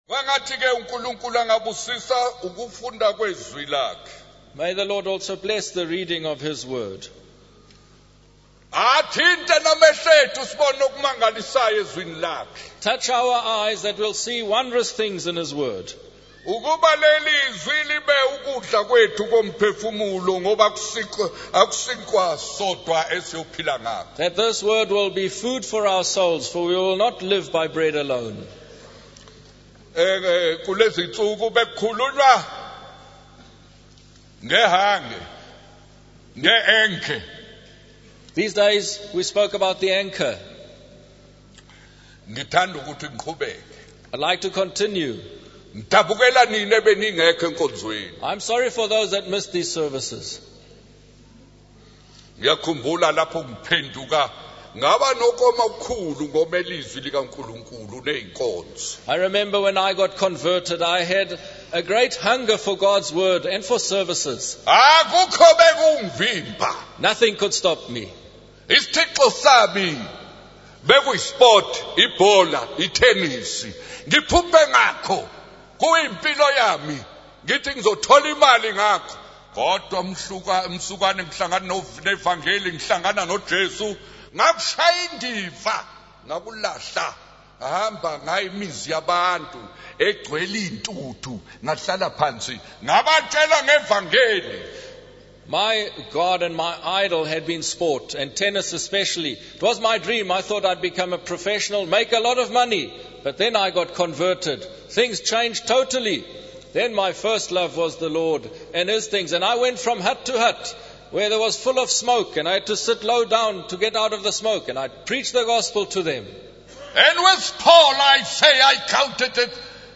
In this sermon, the preacher emphasizes the importance of having an anchor in life.